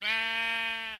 sheep